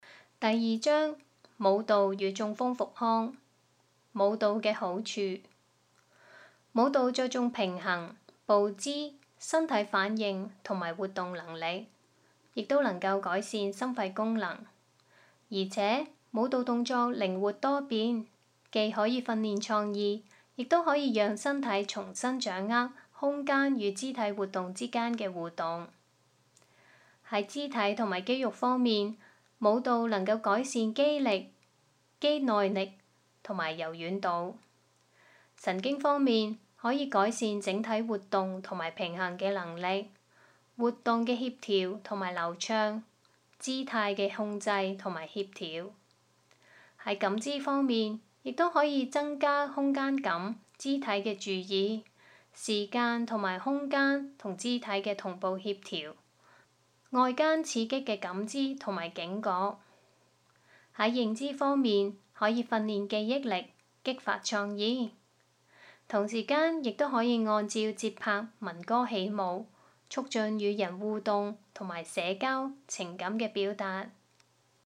聲音導航